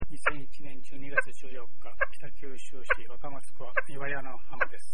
岩屋の鳴り砂
サンプリングされたままの鳴り砂の発音特性
実験室における鳴り砂ポットでの音
1. きれいな鳴り砂の発音特性を示している。